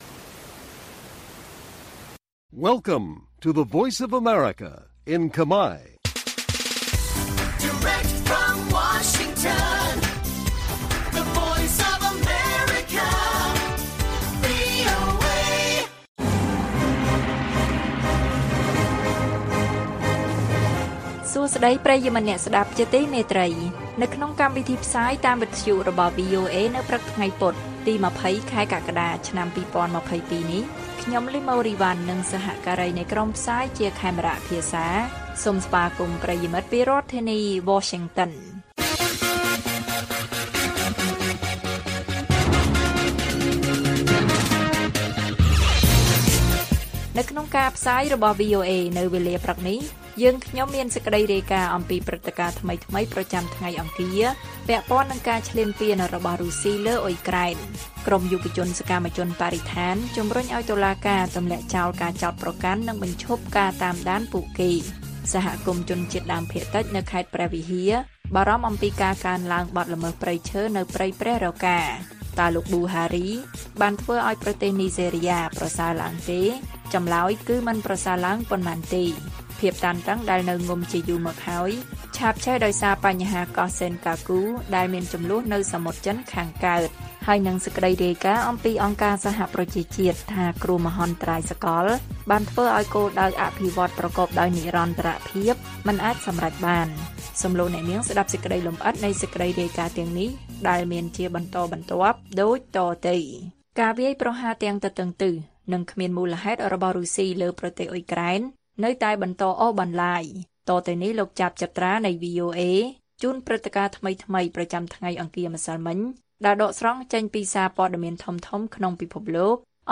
ព័ត៌មានពេលព្រឹក ២០ កក្កដា៖ ក្រុមយុវជនសកម្មជនបរិស្ថានជំរុញឲ្យតុលាការទម្លាក់ចោលការចោទប្រកាន់ និងបញ្ឈប់ការតាមដានពួកគេ